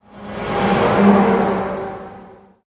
car10.ogg